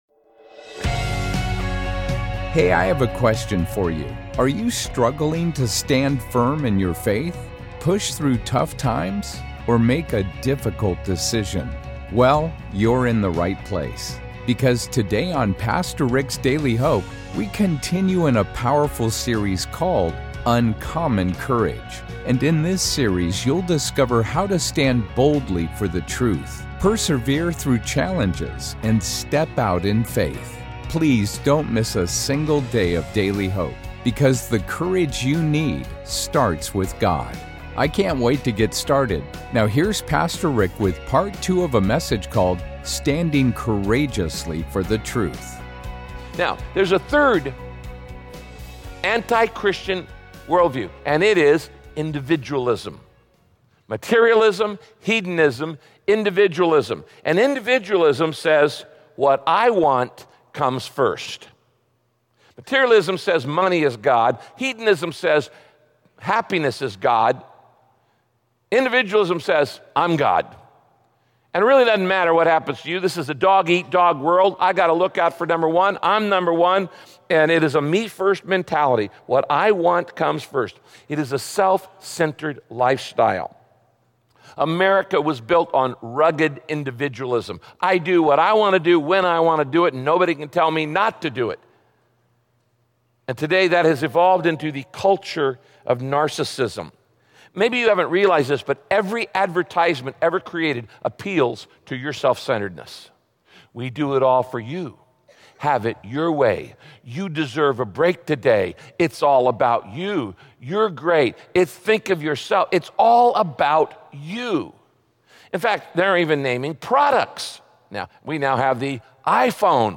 Many people make happiness their number one goal. In this message, Pastor Rick explains why happiness is a byproduct—not the goal—of living the purpose for whic…